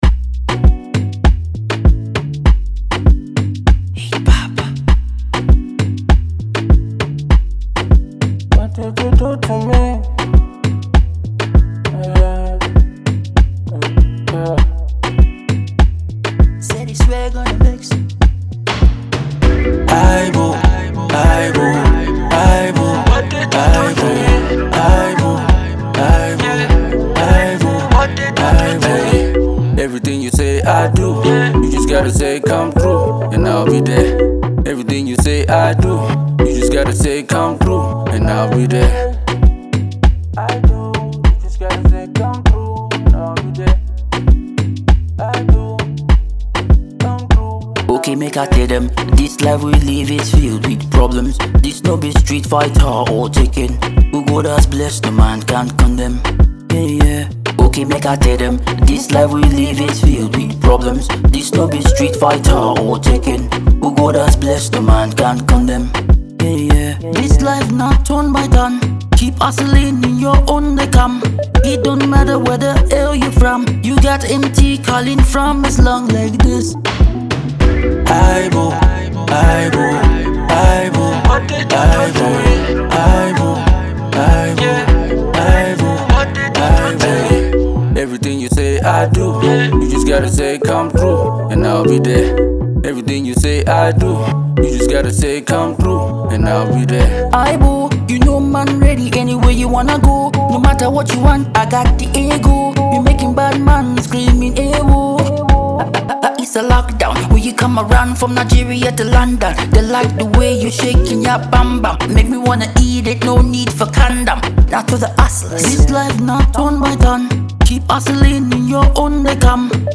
an Afro Beat instrumental with an auto tuned trap cadence